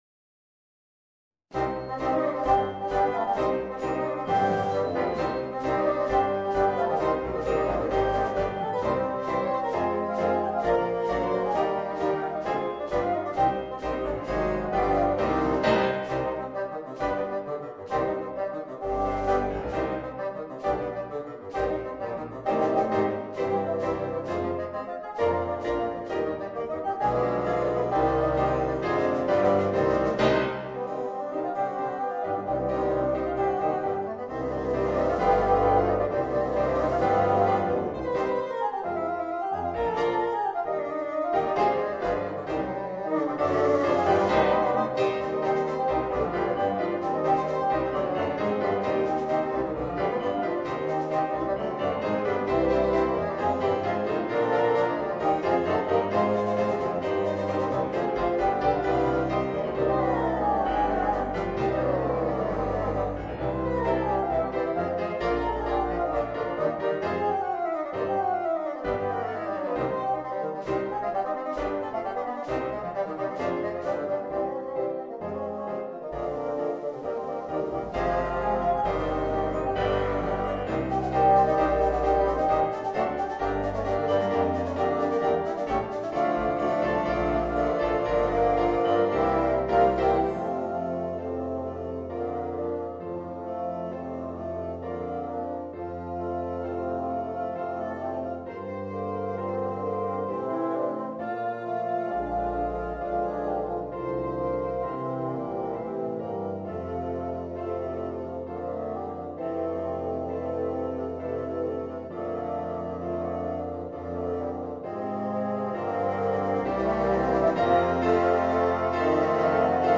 8 Fagotte (Klavier, Pauken, Schlagzeug optional)